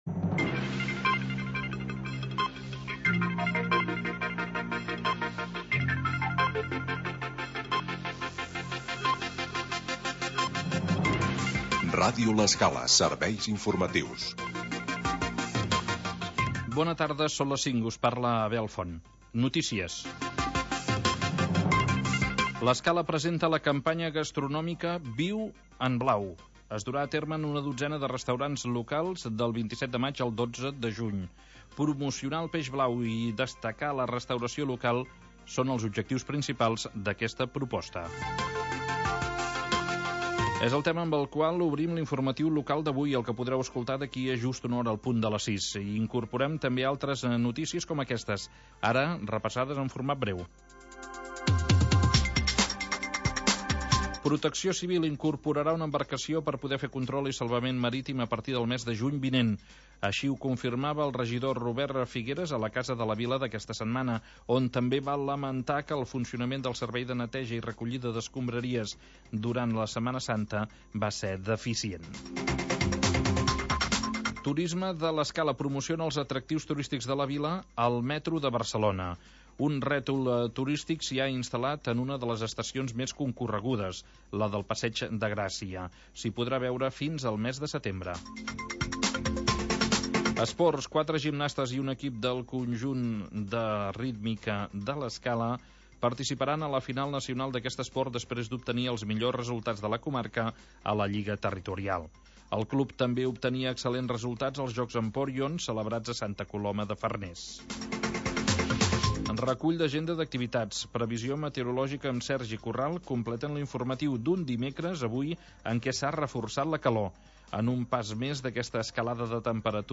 Magazín territorial que parla del mar